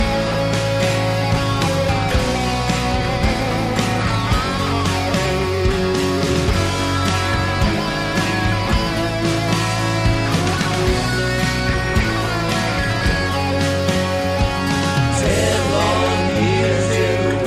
A healthy handful of chords, tautly played.